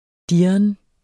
Udtale [ ˈdiɐ̯ən ]